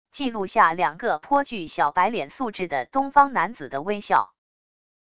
记录下两个颇具小白脸素质的东方男子的微笑wav音频